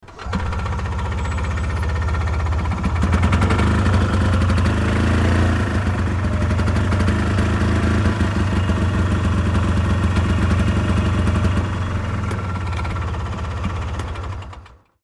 Звуки гольфа
Звук: бензиновый гольфкар завелся и заглох